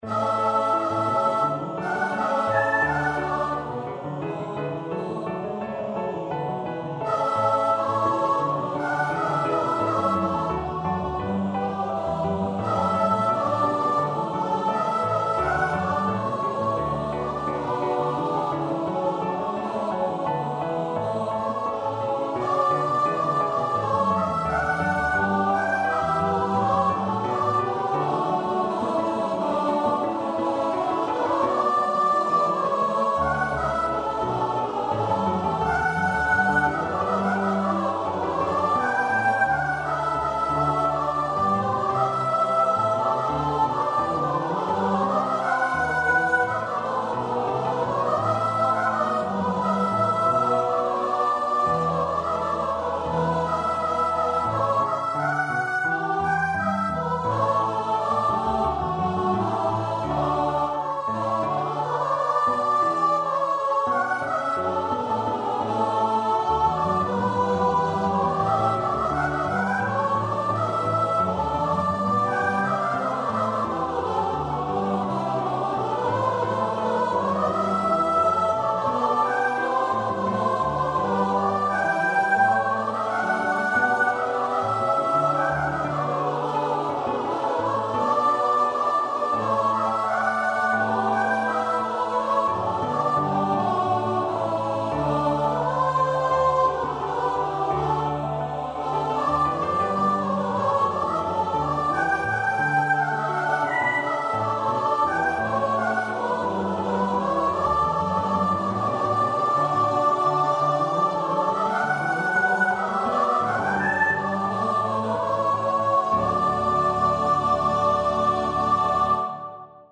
Free Sheet music for Choir (SATB)
Choir  (View more Intermediate Choir Music)
Classical (View more Classical Choir Music)